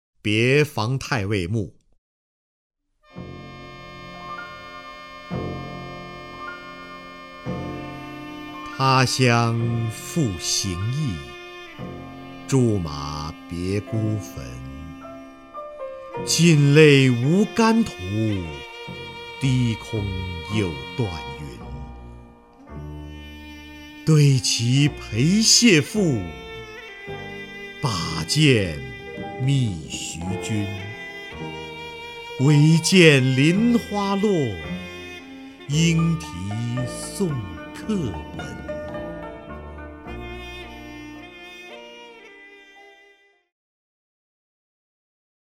首页 视听 名家朗诵欣赏 瞿弦和
瞿弦和朗诵：《别房太尉墓》(（唐）杜甫)